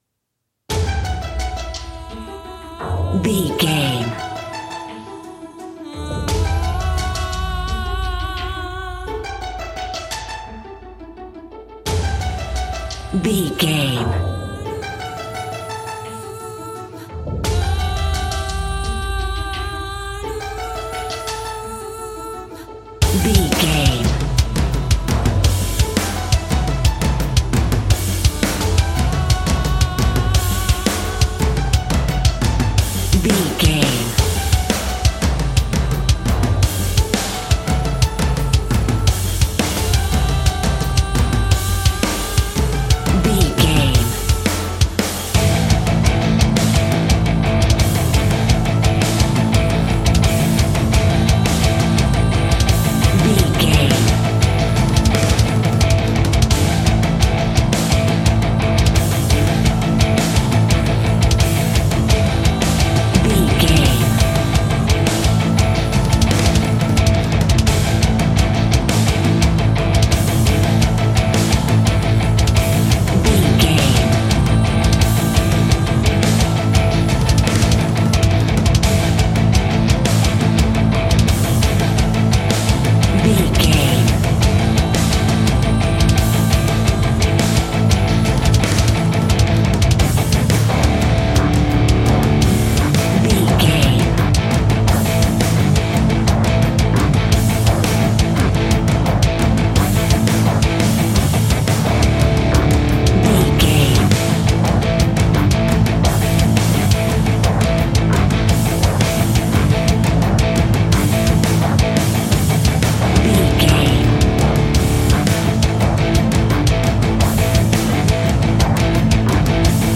Epic / Action
Fast paced
Aeolian/Minor
guitars
heavy metal
dirty rock
scary rock
Heavy Metal Guitars
Metal Drums
Heavy Bass Guitars